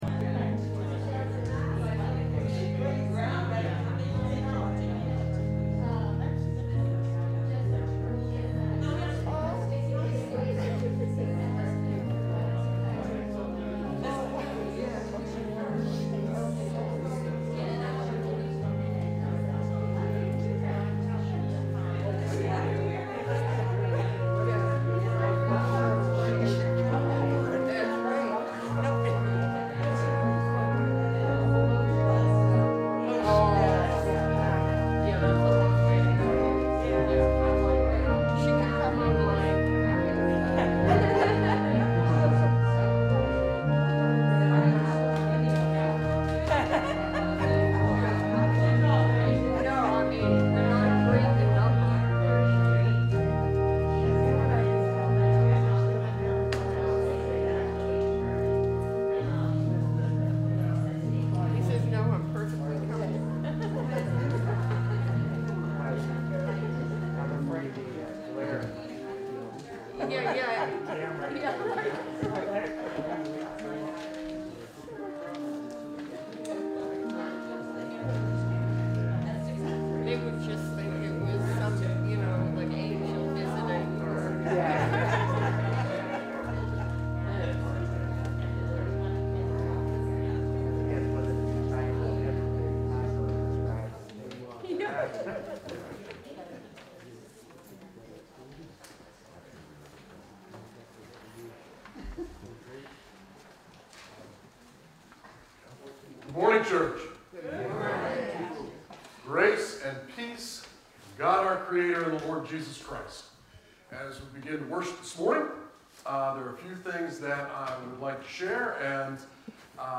We welcome you to either virtual or in-person worship on Sunday, August 15, 2021 at 10am!